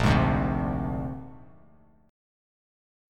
Bb9 Chord
Listen to Bb9 strummed